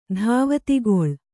♪ dhāvatigoḷ